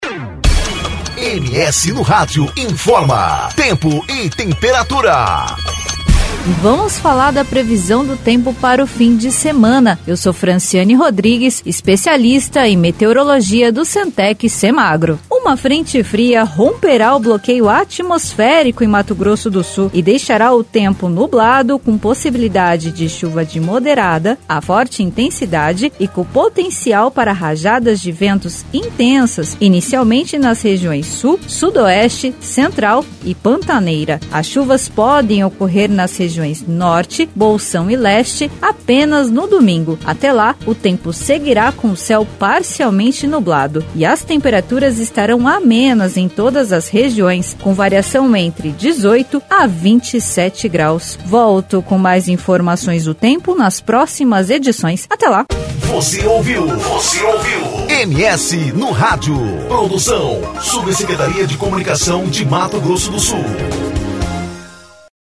Previsão do Tempo